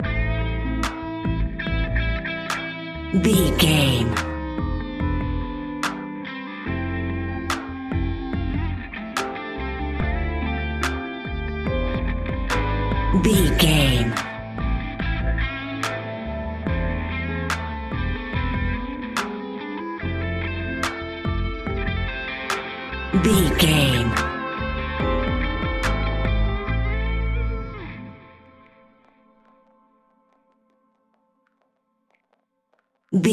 Ionian/Major
A♭
hip hop
instrumentals